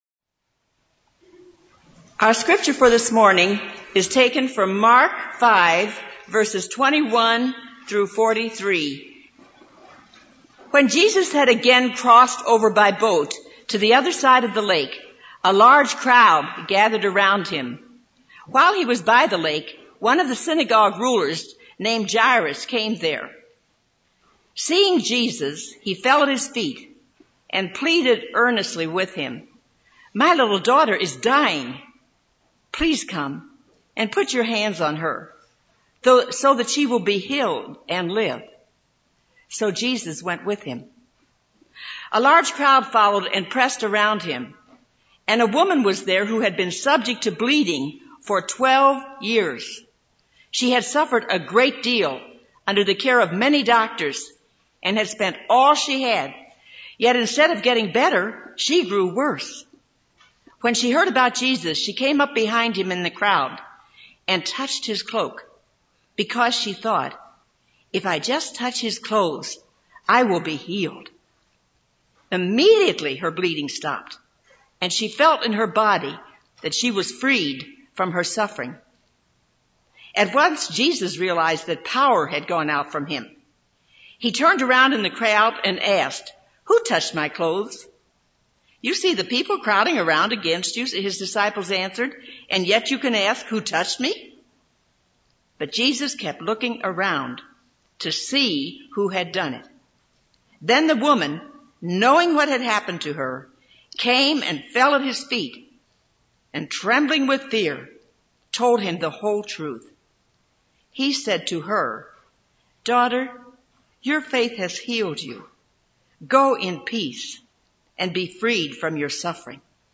This is a sermon on Mark 5:21-43.